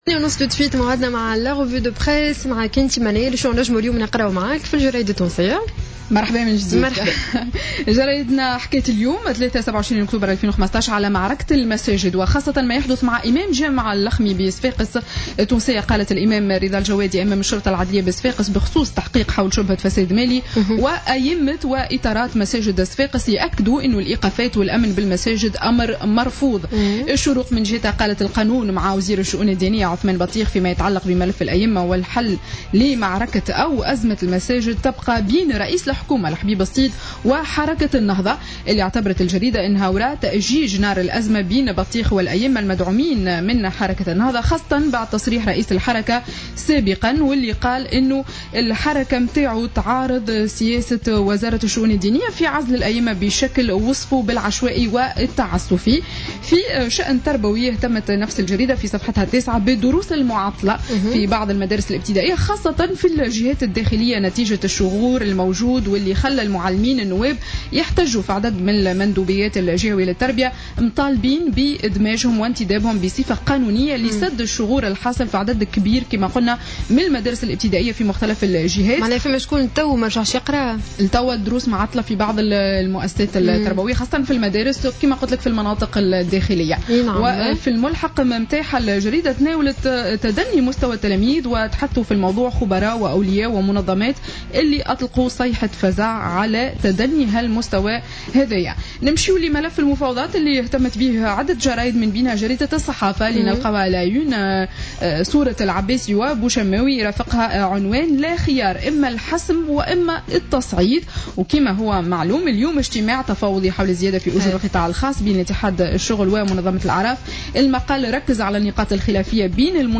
Revue de presse 27/10/2015 à 08:38